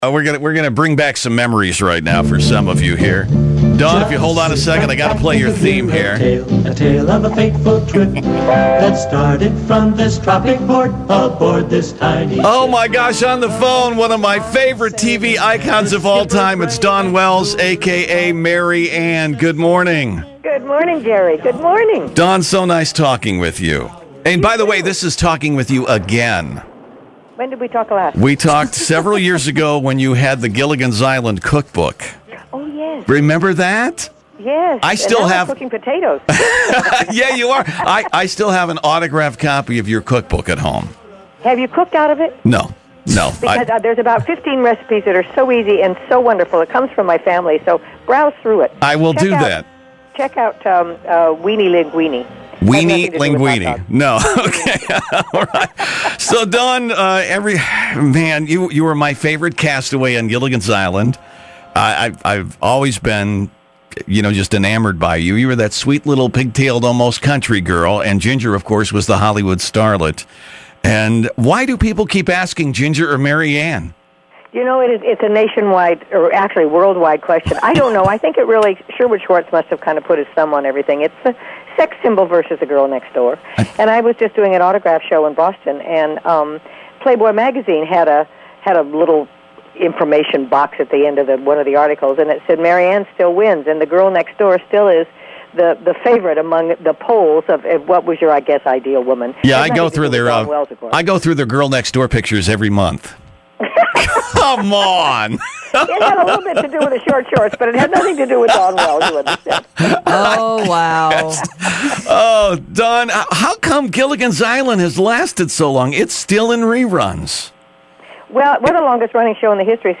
Another long-lost interview from my archives; Dawn Wells — just following her arrest for marijuana posession 😉